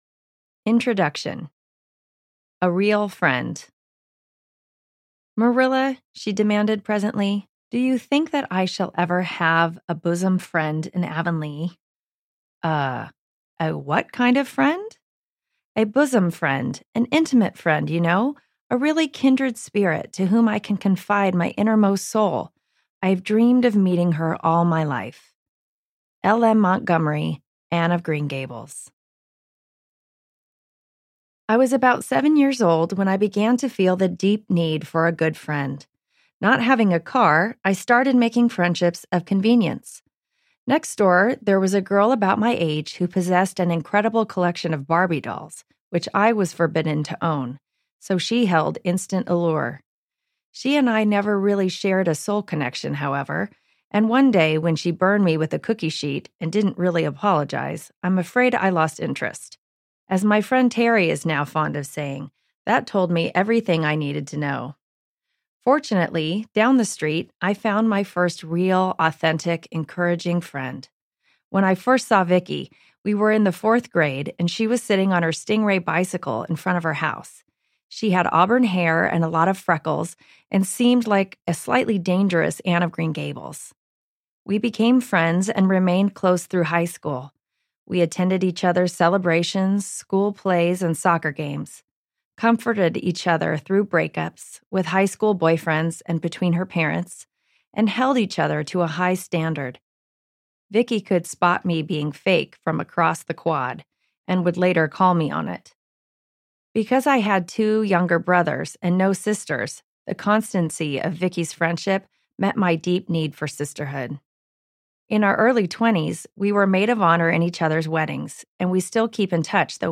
All My Friends Have Issues Audiobook